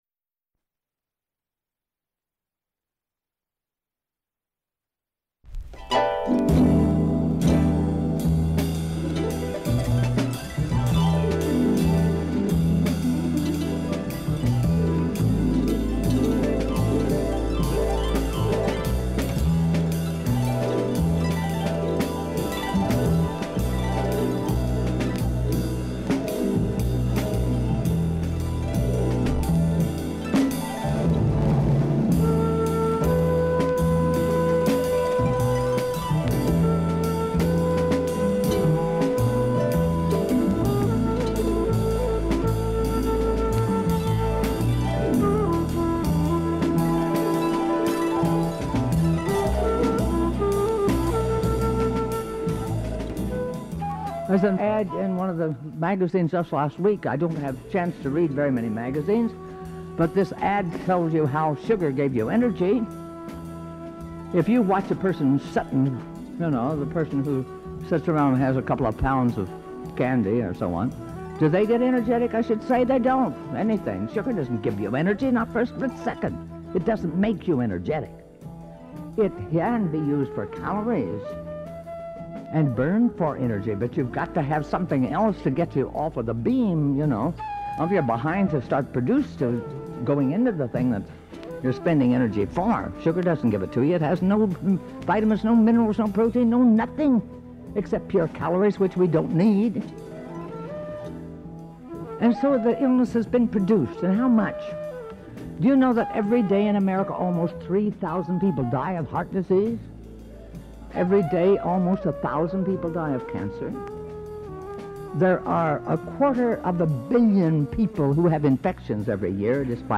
Davis, Adelle (speaker) Lecture by, Adelle Davis, June 4, 1972
This discussion was first broadcast on Women’s Hour, June 4, 1972. Nutritionist Adelle Davis explores food and the state of the American diet.